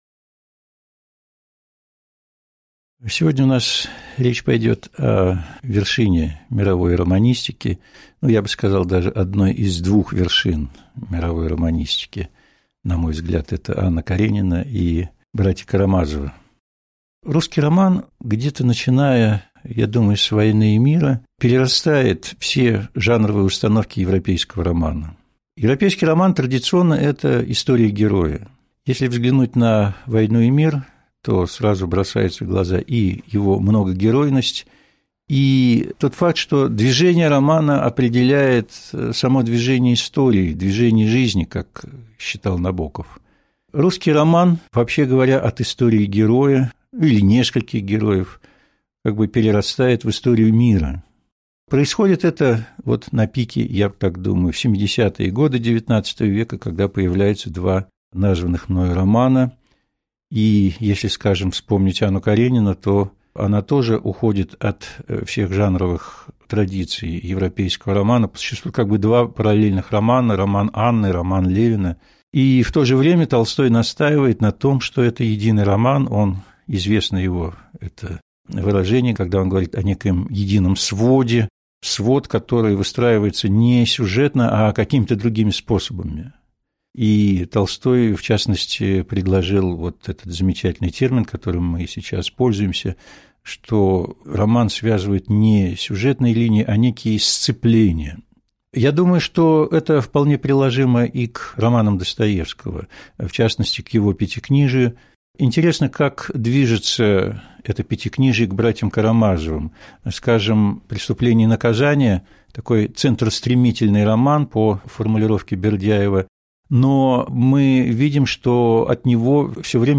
Аудиокнига Лекция «Братья Карамазовы». Дан ли ответ Великому инквизитору?»